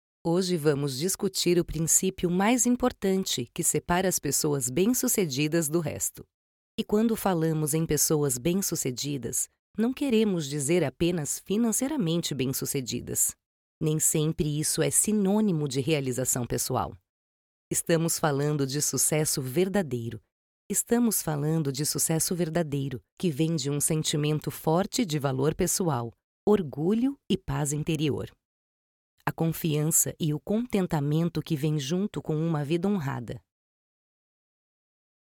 Sprechprobe: Sonstiges (Muttersprache):
My vocal personality ranges from a youth, which inspires lightness, energy and friendliness, to a “maturity”, which conveys confidence, calm and drama. Own studio in the countryside of São Paulo, Brazil. With an excellent acoustic treatment system that offers excellent quality.